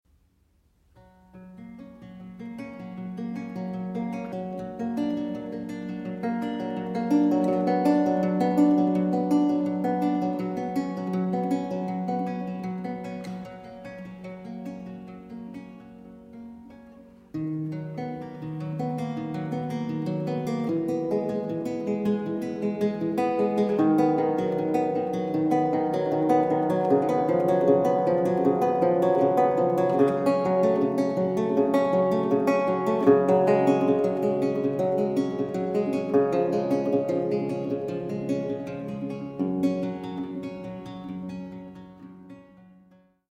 Baroque Guitar and Theorbo
Italská kytarová a theorbová hudba ze 17. století
Kaple Pozdvižení svatého Kříže, Nižbor 2014